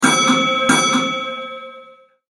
Den Tram ass en Instrument vum Orchester.
Sample_Tram_2.mp3